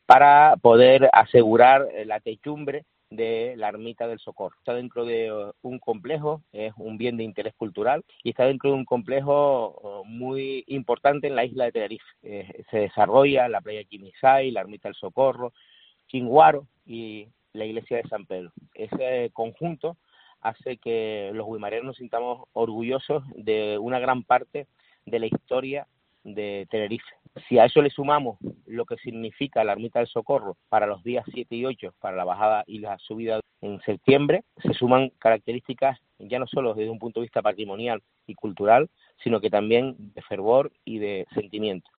El alcalde de Güímar, Gustavo Pérez, destaca el convenio para restaurar la ermita de El Socorro